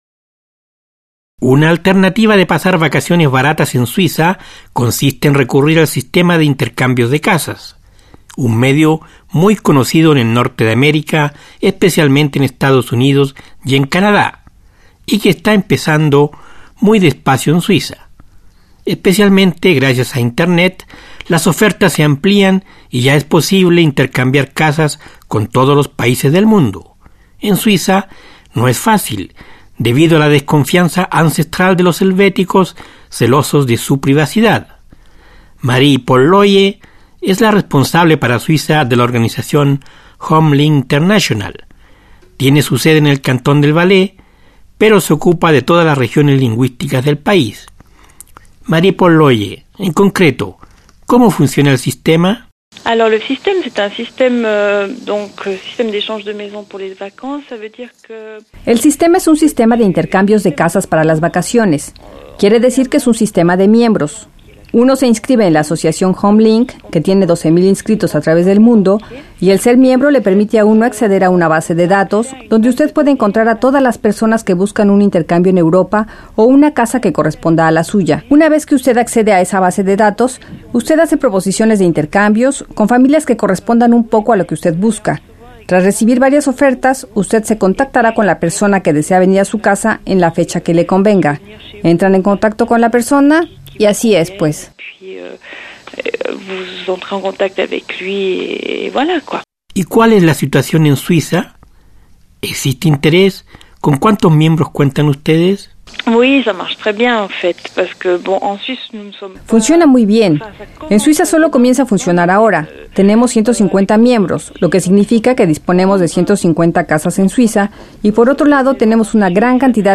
Un reportaje